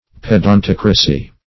Search Result for " pedantocracy" : The Collaborative International Dictionary of English v.0.48: Pedantocracy \Ped`an*toc"ra*cy\, n. [Pedant + democracy.] The sway of pedants.